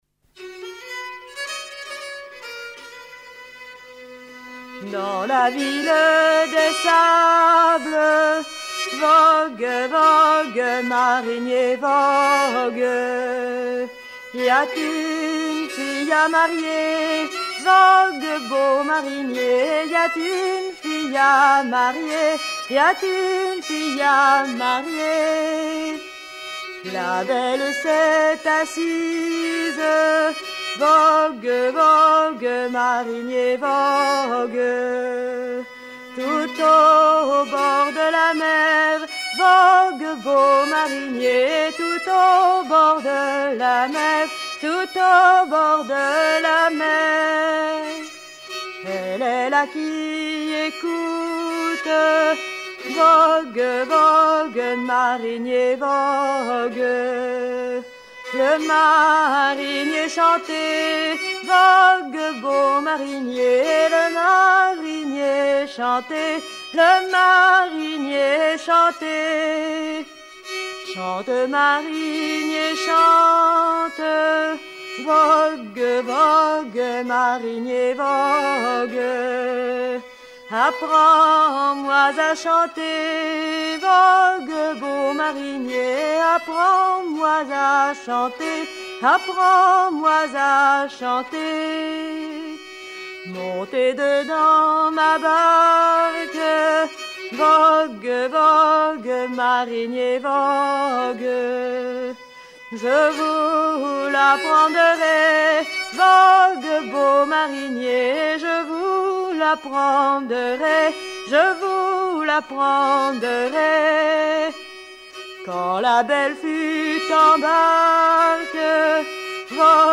Genre strophique
Edition discographique Chants de marins traditionnels, vol. I à V
Catégorie Pièce musicale éditée